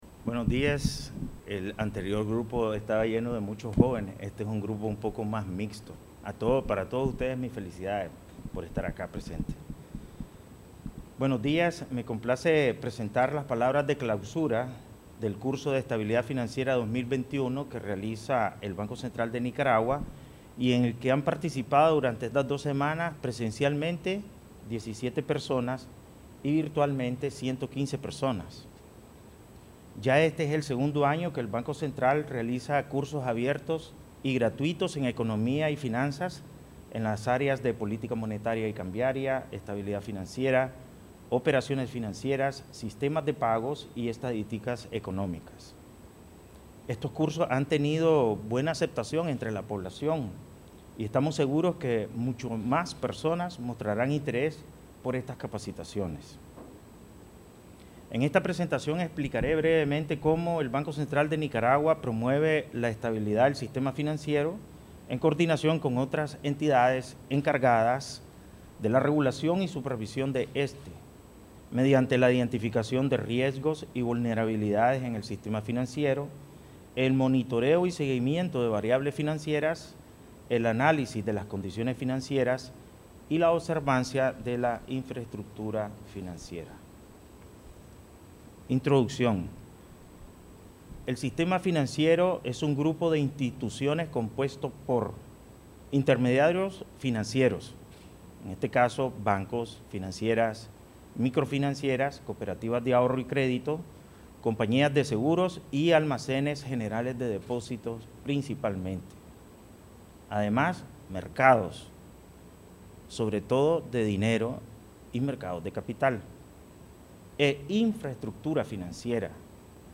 Durante la clausura, el Presidente del BCN, Ovidio Reyes R., indicó que este es el segundo año que la institución realiza cursos abiertos y gratuitos en economía y finanzas en las áreas de: política monetaria y cambiaria, estabilidad financiera, operaciones financieras, sistemas de pagos y estadísticas económicas, los cuales han tenido buena aceptación entre la población.
Palabras_Presidente_BCN_en_clausura_Curso_de_Estabilidad_Financiera_2021.mp3